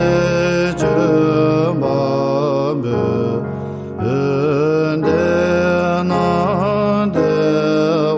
Chants Sacrés Tibétains